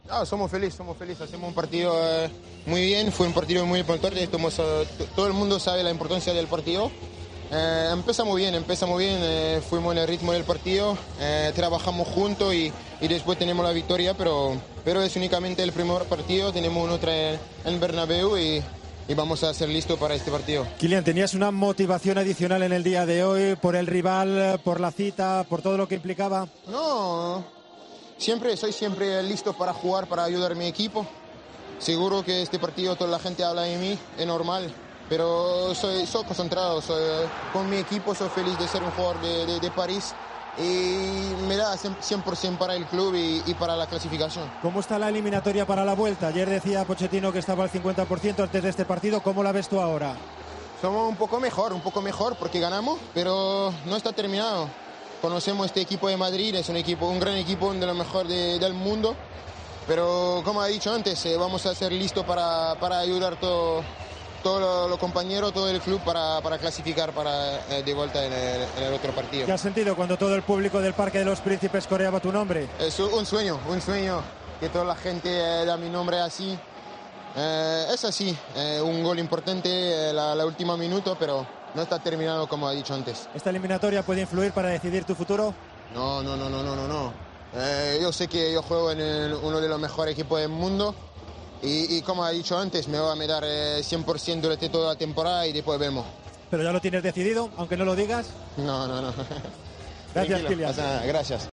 El francés ha atendido a Movistar en un perfecto castellano tras haber sido el mejor jugador del partido ante el Real Madrid.